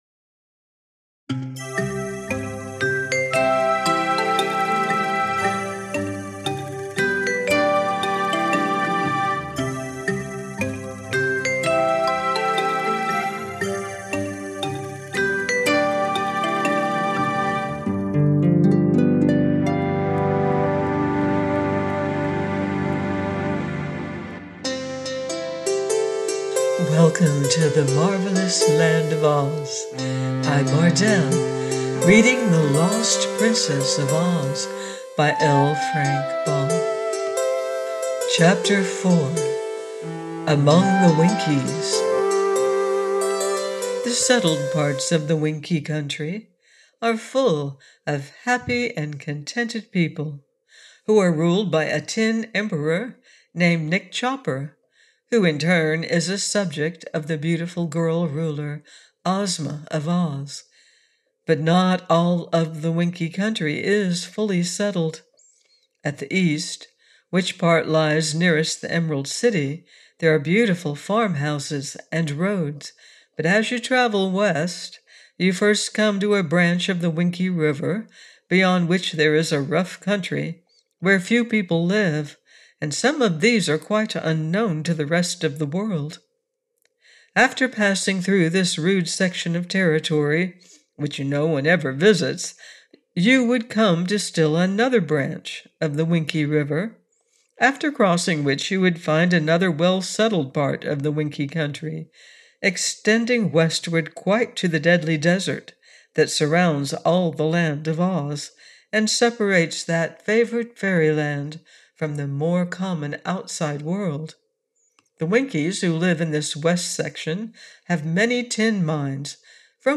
The Lost Princess of Oz by Frank L. Baum - AUDIOBOOK